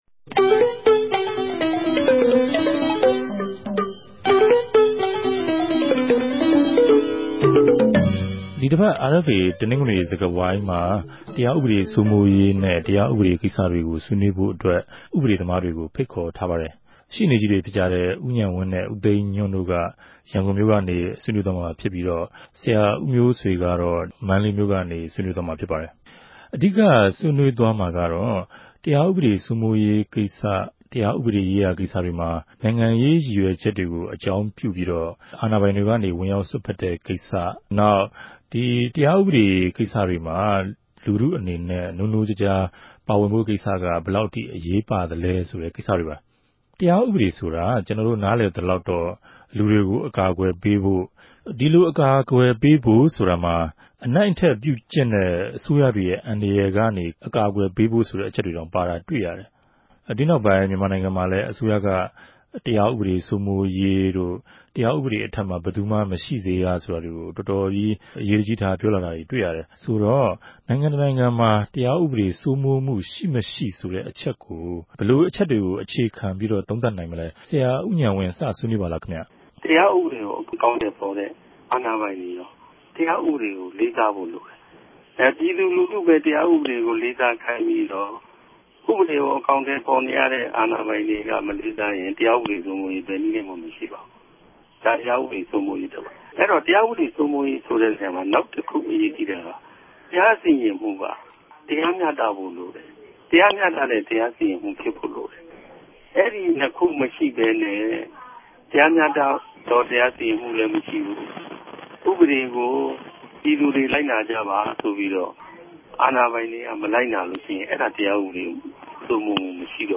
ိံိုင်ငံရေး သဘောထားအူမင် မတူသူတေအြပေၞ တရားဥပဒေ ပုဒ်မ အမဵိြးမဵိြးနဲႛ တရားစြဲဆို္ဘပီး အဋ္ဌကီးလေးဆုံး ူပစ်ဒဏ်တြေ ခဵမြတ်နေတာတြေ၊ လက်ရြိ ဥပဒေနဲႛ မညီႌြတ်တဲ့ လုပ်ထုံးလုပ်နည်းတြေ၊ ူမန်မာိံိုင်ငံမြာ ူဖစ်ပေၞနေတာဟာ ိံိုင်ငံရဲ့ တရားဥပဒေ စိုးမိုးရေးအပေၞ သက်ရောက်လာိံိုင်တဲ့ အေုကာင်းအကဵိြးတေကြို ူမန်မာိံိုင်ငံထဲမြ ဥပဒေပညာရြင် သုံးယောက်က သုံးသပ်ဆြေးေိံြးထားပၝတယ်။
တနဂဿေိံြ ဆြေးေိံြးပြဲစကားဝိုင်း